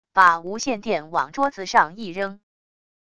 把无线电往桌子上一扔wav音频